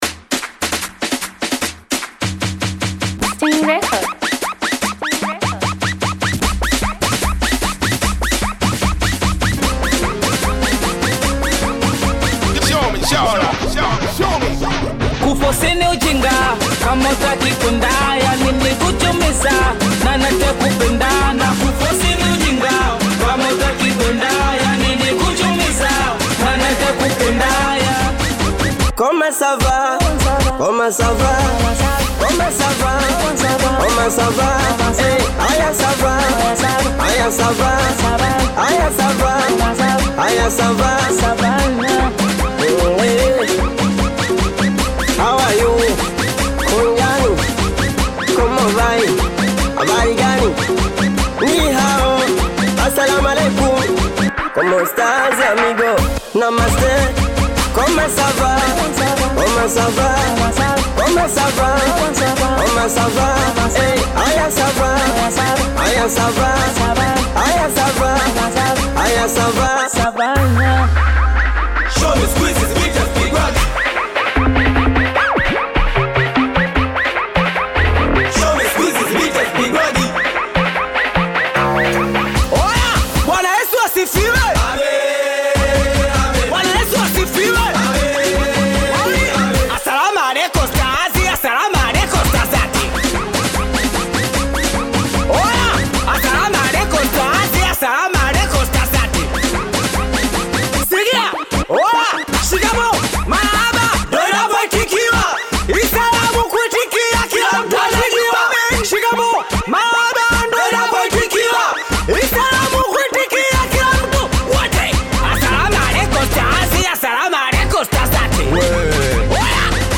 Tanzanian Bongo Flava artists
Singeli song